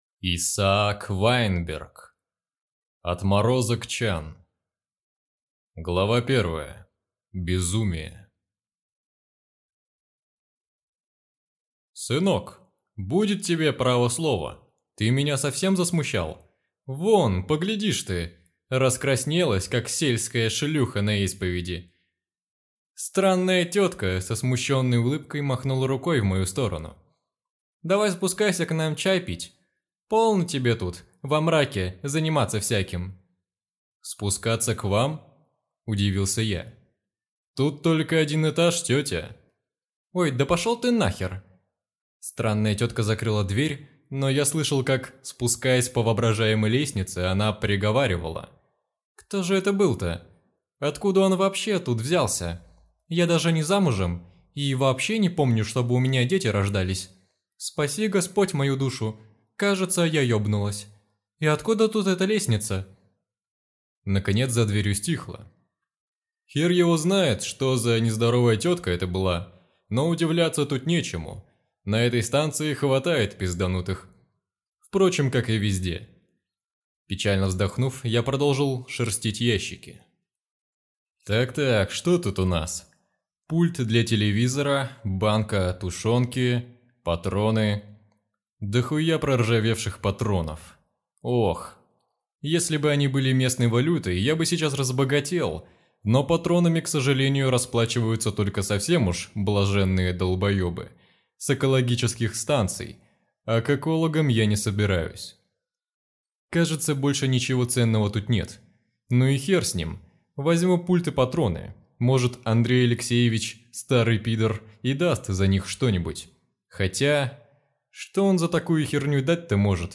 Аудиокнига Отморозок Чан | Библиотека аудиокниг